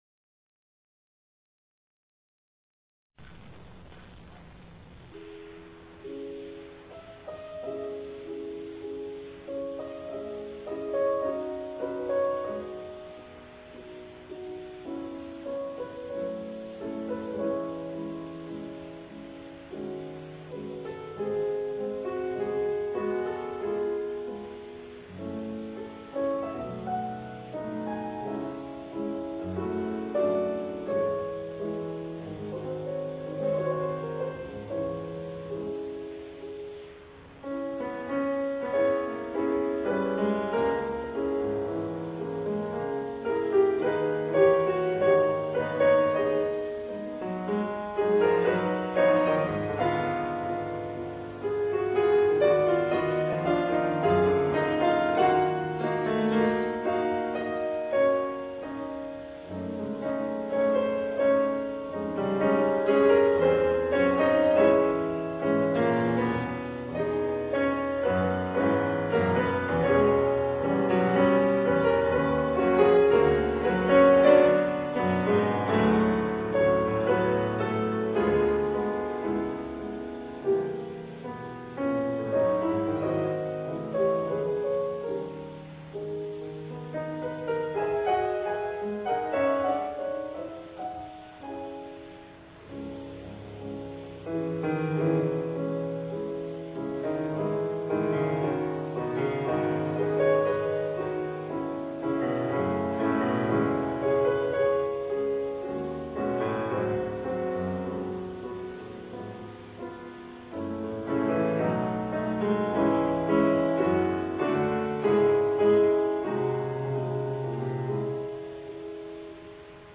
この演奏会は福井の「ユー・アイふくい」多目的ホールで行なわれました。
当日はトークを交えて楽しいコンサートとなりましたが、自編曲が私のピアノのテクニックを超えるハードだったため、体力的にも限界状態の演奏もあります。
残りの２曲は、アンコールとして演奏しました。